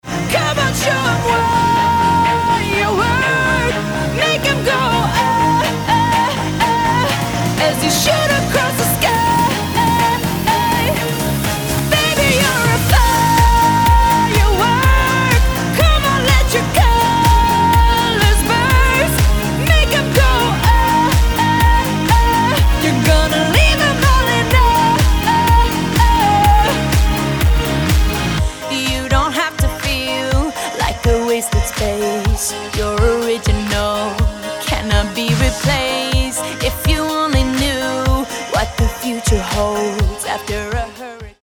pop-rocková americká speváčka